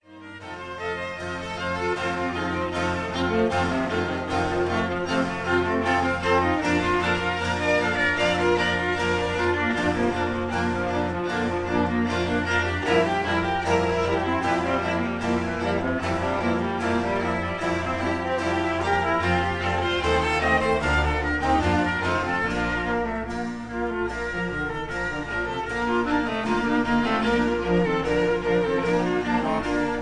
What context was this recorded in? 1960 stereo recording made by